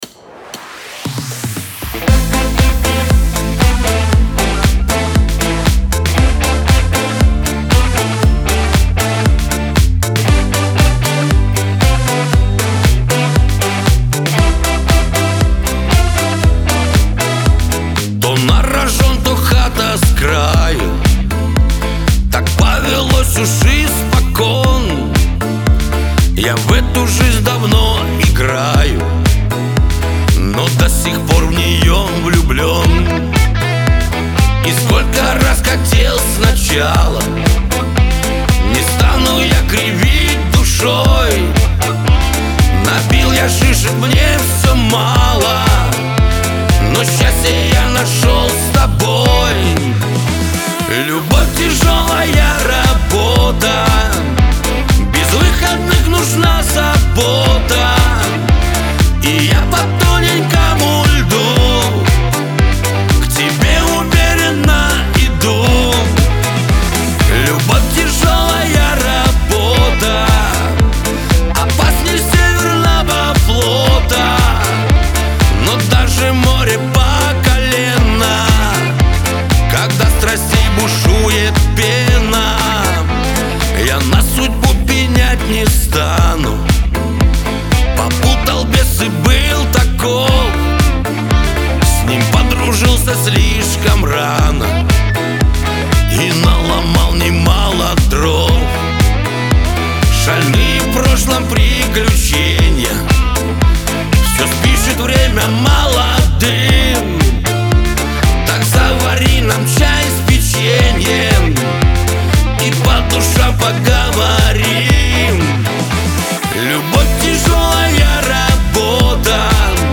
ХАУС-РЭП
Кавер-версия
дуэт